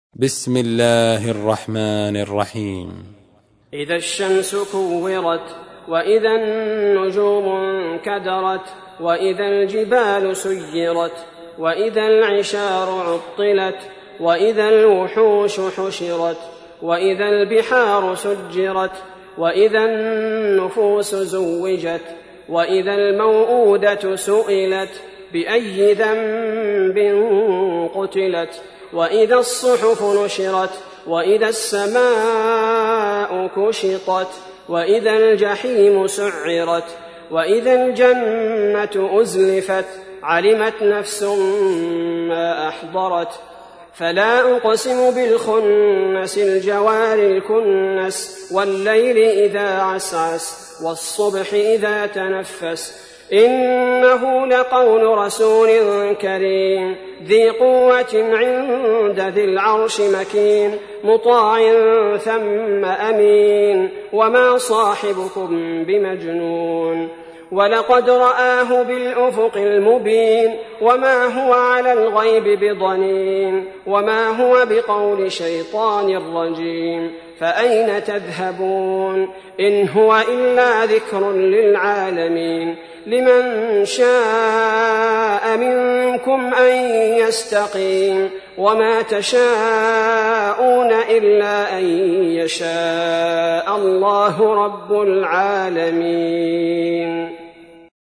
تحميل : 81. سورة التكوير / القارئ عبد البارئ الثبيتي / القرآن الكريم / موقع يا حسين